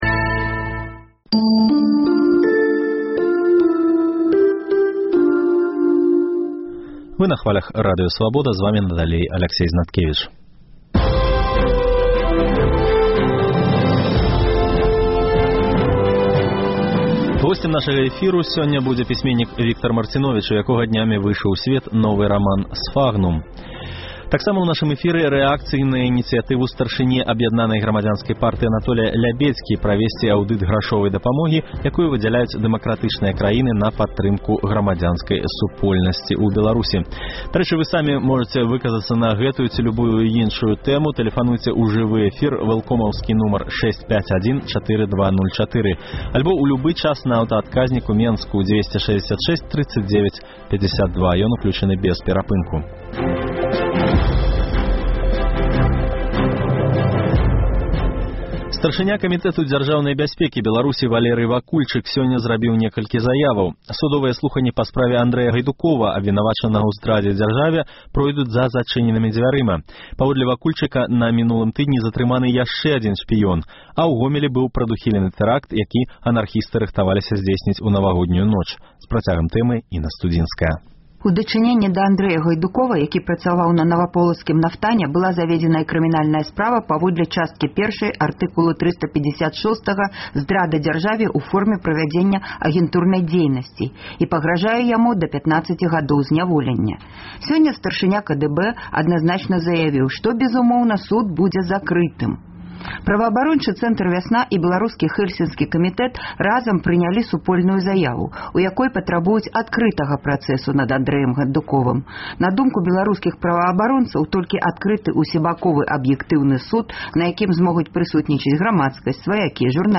Упершыню беларускі раман адначасова выходзіць адразу на дзьвюх плятформах і на дзьвюх мовах – па-расейску, на мове арыгіналу, у электронным выглядзе, і папяровым выданьнем у перакладзе на беларускую. Таксама прагучыць інтэрвію